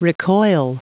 Prononciation du mot recoil en anglais (fichier audio)
Prononciation du mot : recoil